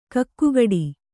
♪ kakkugaḍi